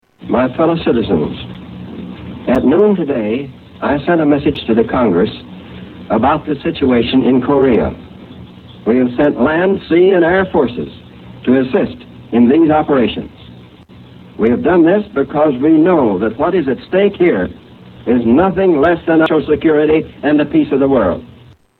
Tags: History Presidents Of the U. S. President Harry S. Truman Speeches